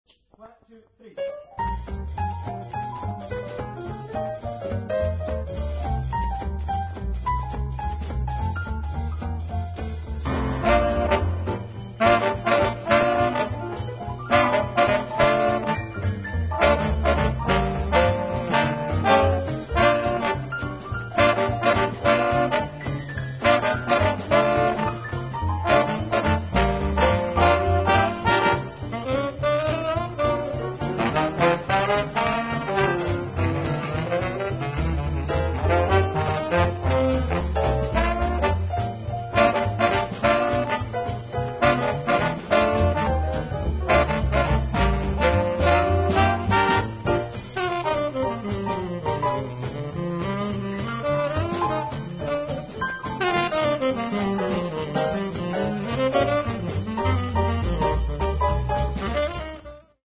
Alcuni brani registrati dal vivo.